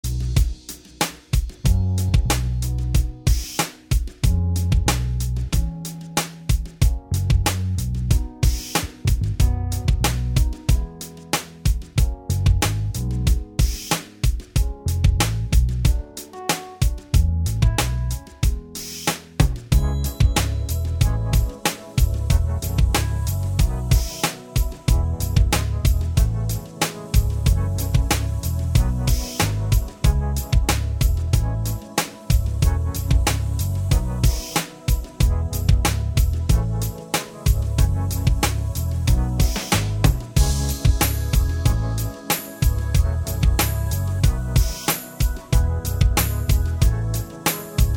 Minus All Guitars Soft Rock 5:30 Buy £1.50